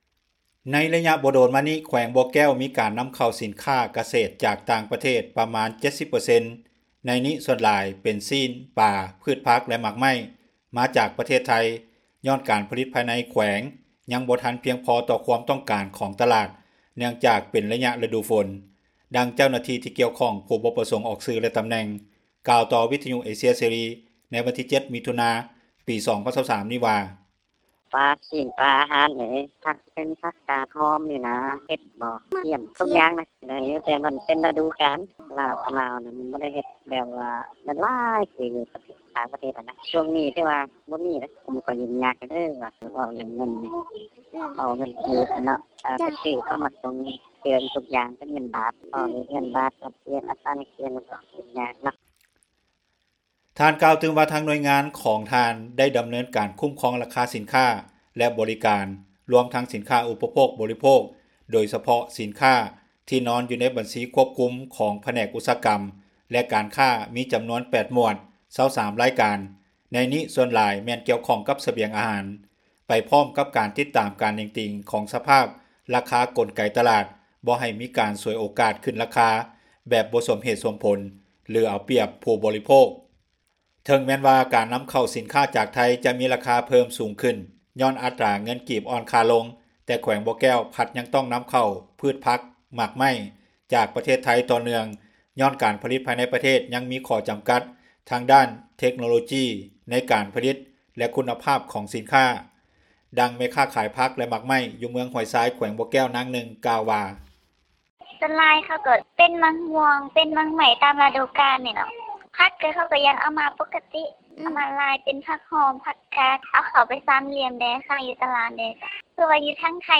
ແຂວງບໍ່ແກ້ວ ນຳເຂົ້າສິນຄ້າກະເສດ ຈາກຕ່າງປະເທດ 70% – ຂ່າວລາວ ວິທຍຸເອເຊັຽເສຣີ ພາສາລາວ